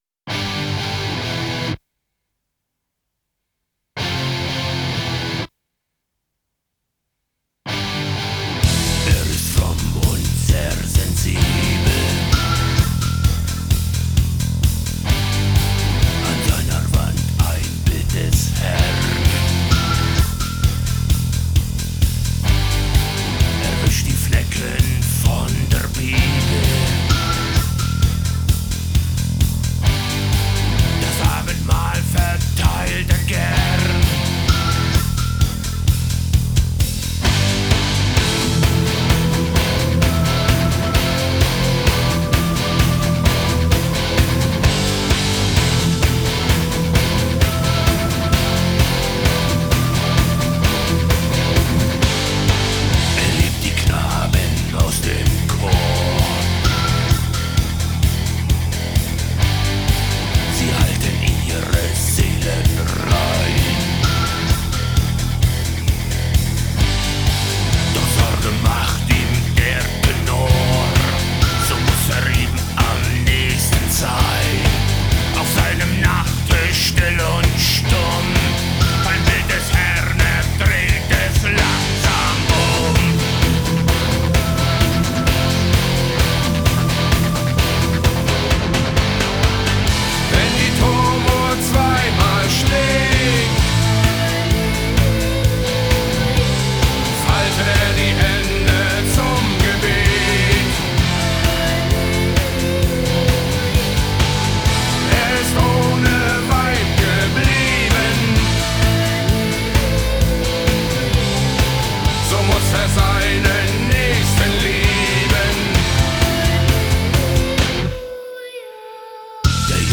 Жанры: Neue Deutsche Herte, индастриал-метал,
хард-рок, готик-метал